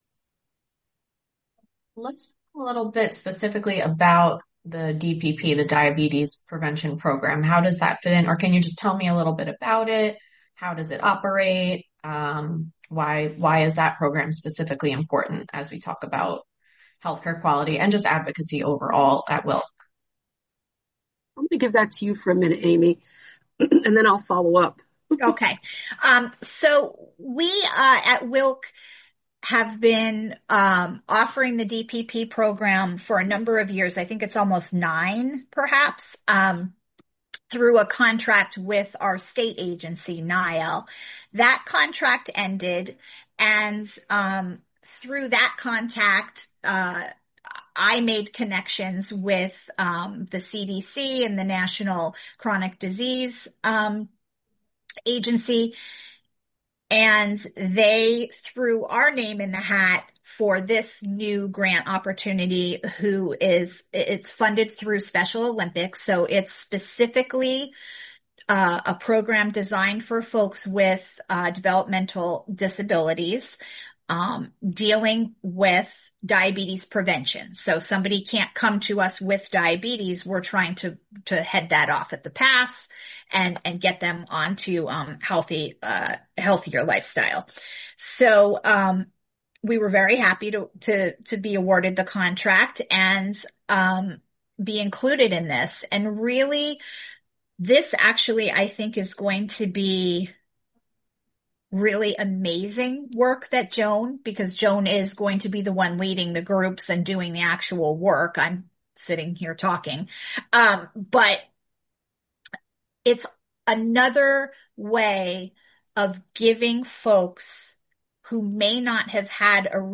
AUDIO TRANSCRIPTION: Let’s talk a little bit specifically about the DPP, the Diabetes Prevention Program.